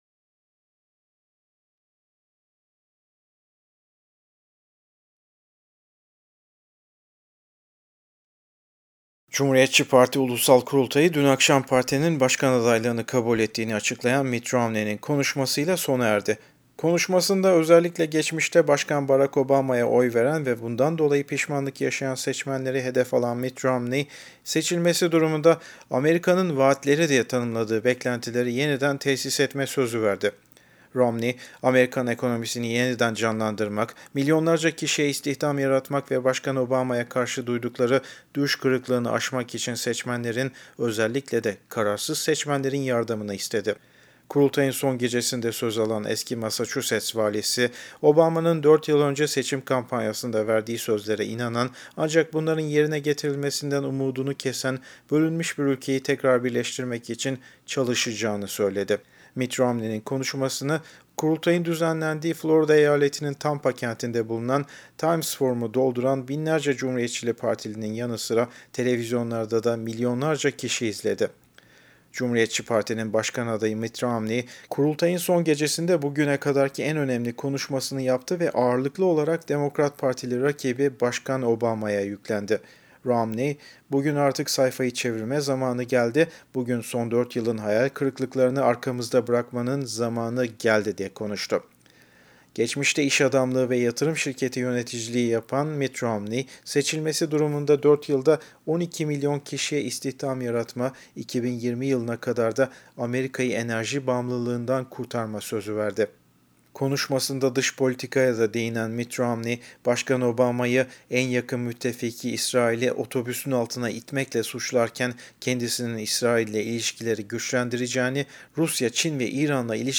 TAMPA, FLORIDA —  Cumhuriyetçi Parti Ulusal Kurultayı, dün akşam partinin başkan adaylığını kabul ettiğini açıklayan Mitt Romney’in konuşmasıyla sona erdi.
Romney'in Konuşması